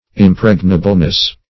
impregnableness - definition of impregnableness - synonyms, pronunciation, spelling from Free Dictionary
-- Im*preg"na*ble*ness, n. -- Im*preg"na*bly, adv.